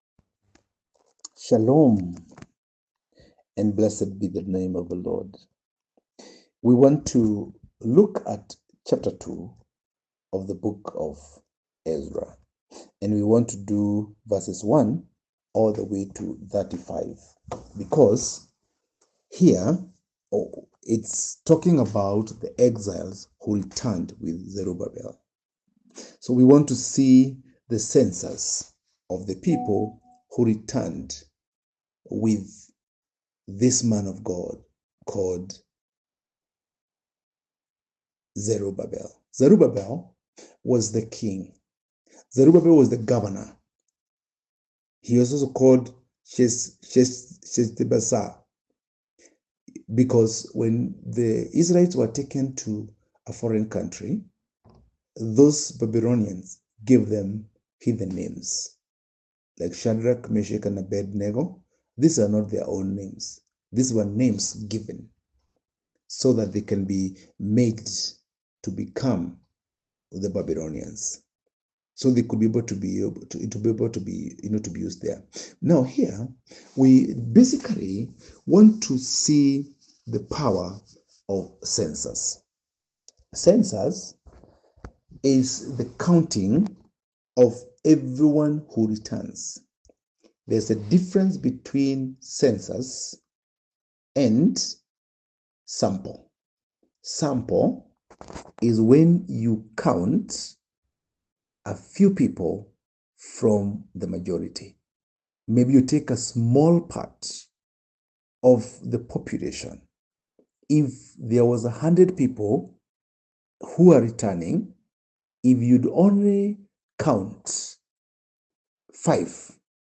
The Census of the people who returned Ezra 2: 1-35 Ezra-2-1-35 Audio Summary Ezra 2:1-35 Context The chapter records the census of the first group of exiles who returned from Babylon under Zerubbabel’s leadership after Cyrus’ decree allowed them to return and rebuild the temple.